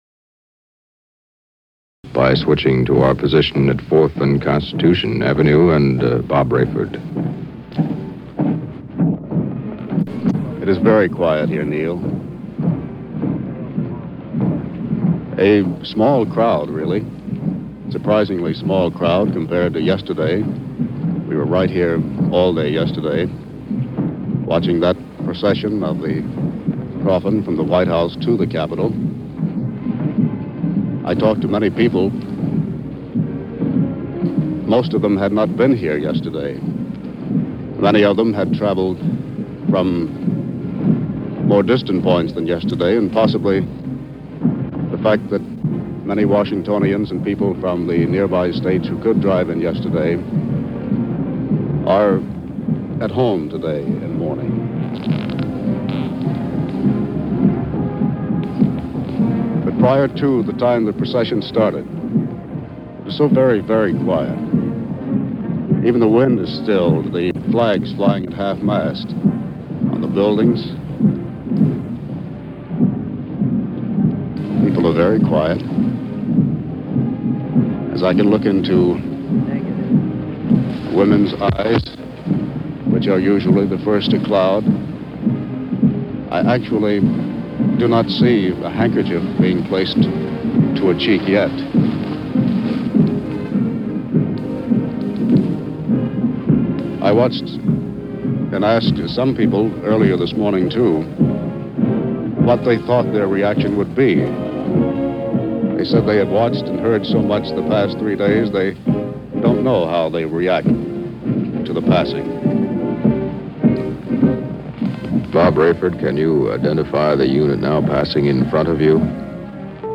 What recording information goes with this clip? reporting from the JFK funeral procession in 1963.